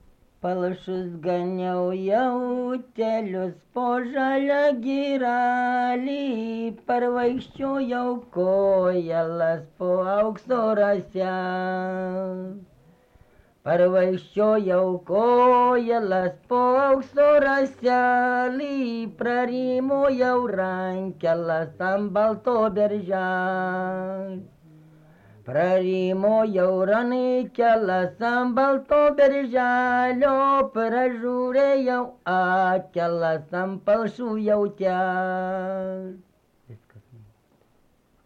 Subject daina
Erdvinė aprėptis Mardasavas
Atlikimo pubūdis vokalinis